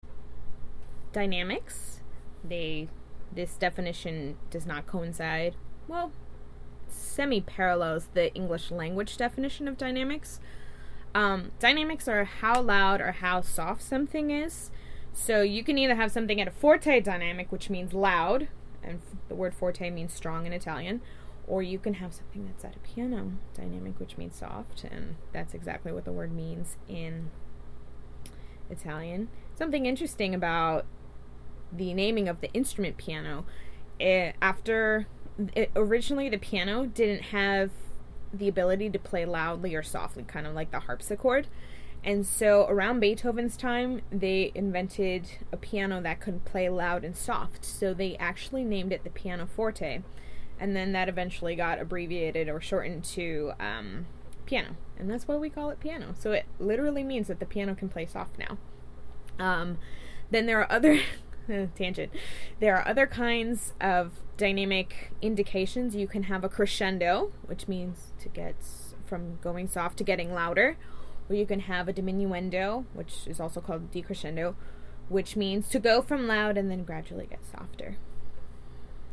• Crescendo – to gradually get louder.
• Decrescendo/diminuendo – to gradually get softer.
• Piano – means soft.
mekdost-dynamics.mp3